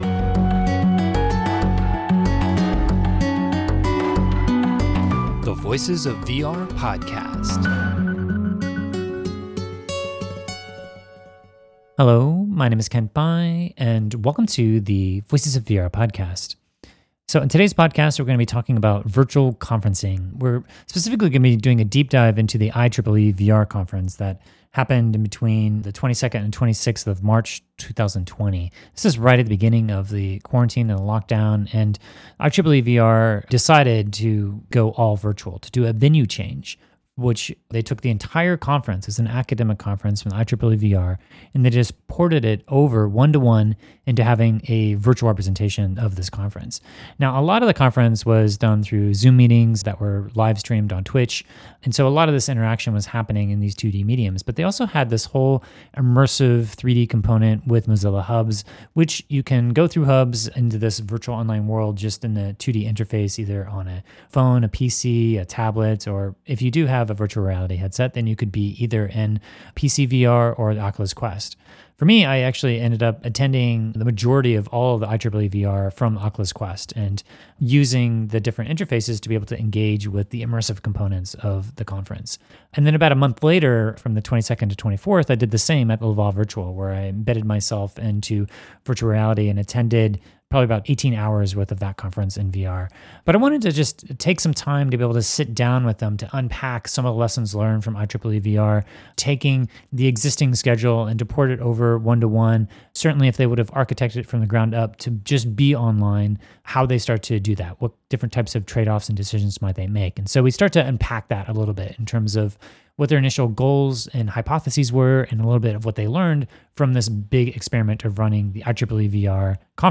This essay will unpack some of my deeper thoughts, experiences, & reflections from IEEE VR based upon a 90-minute conversation that I just had with a couple of the general chairs of the conference.